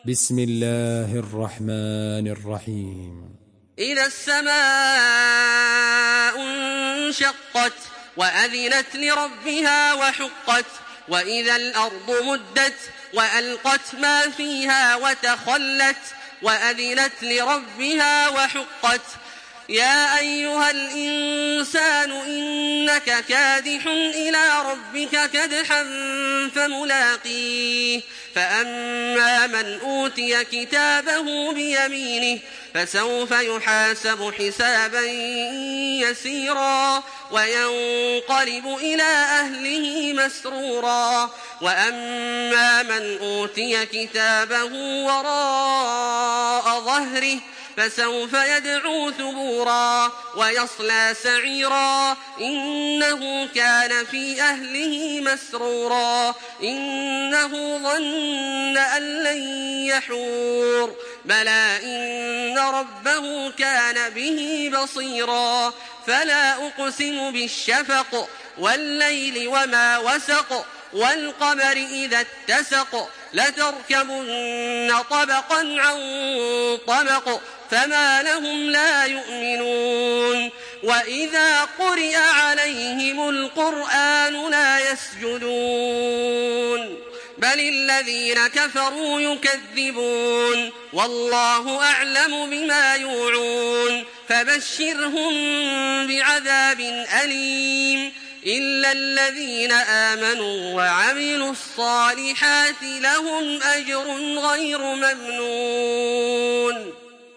Surah Al-Inshiqaq MP3 by Makkah Taraweeh 1426 in Hafs An Asim narration.
Murattal Hafs An Asim